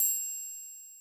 Southside Bell.wav